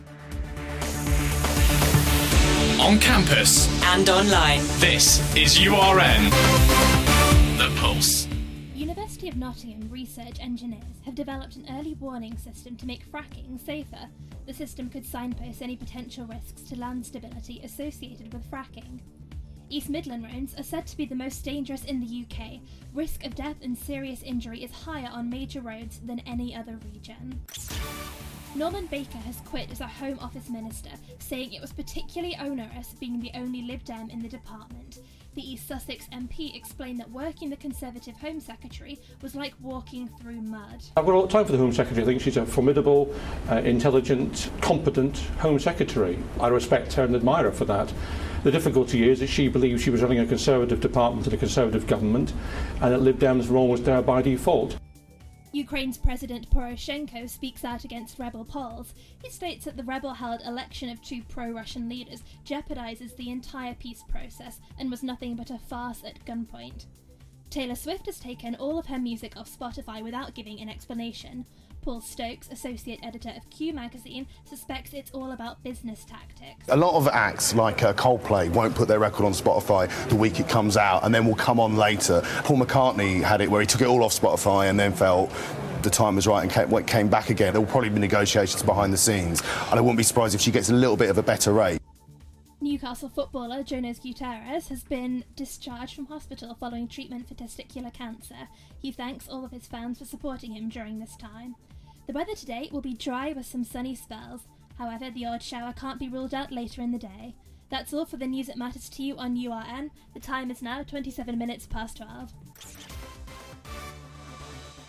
Your Latest Headlines - Tuesday 4th October